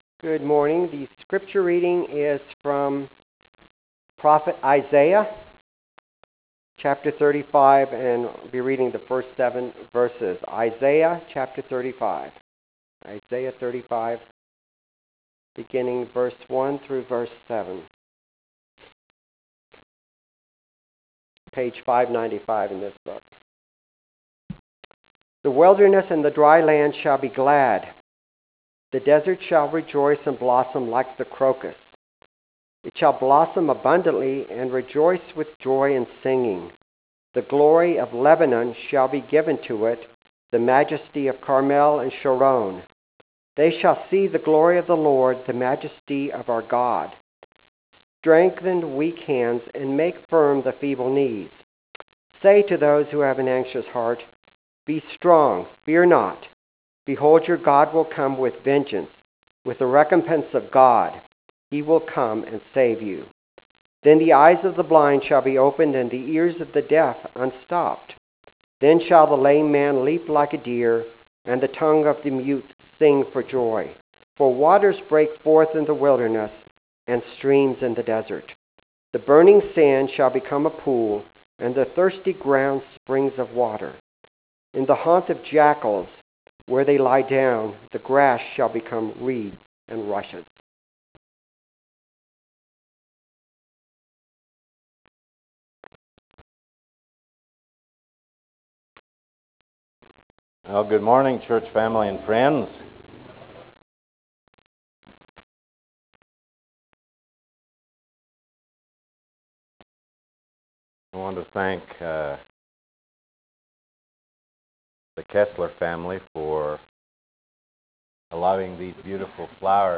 Aug 3 2014 AM sermon